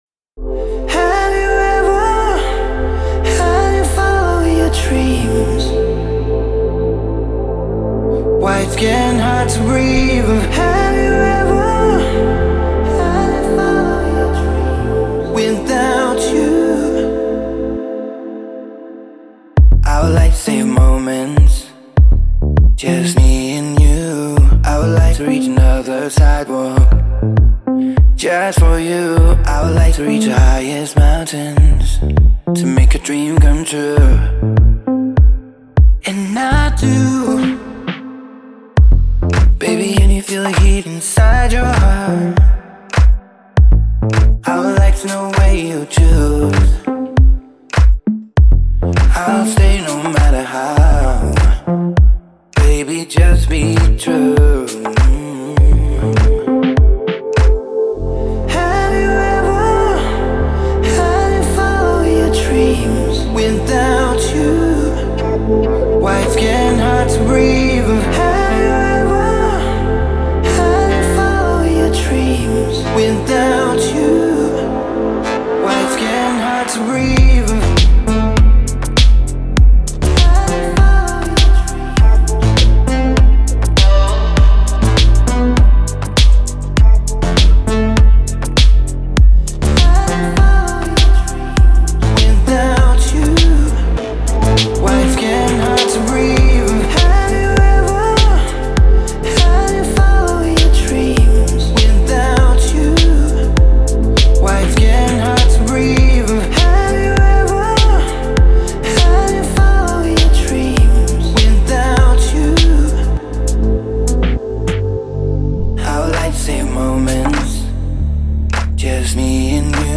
это трек в жанре электронная поп-музыка